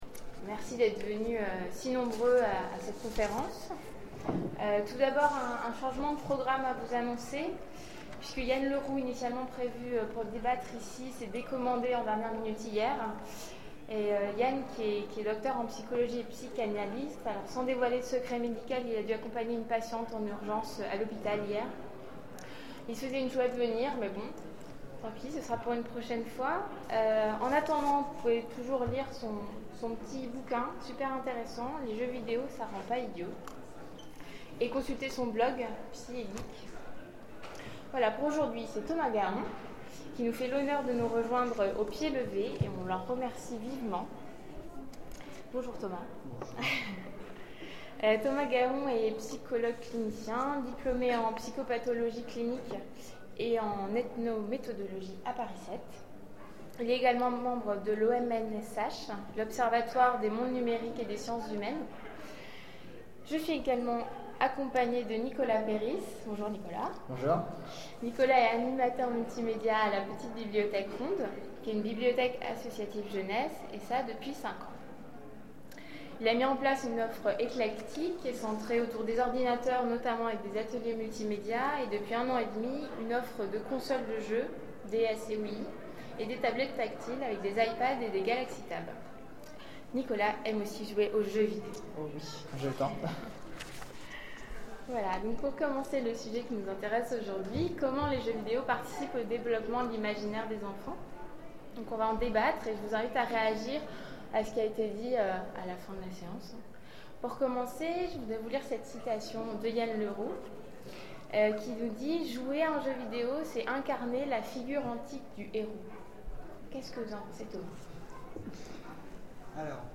Zone Franche 2013 : Conférence Comment les jeux vidéo participent au développement de l'imaginaire des enfants ?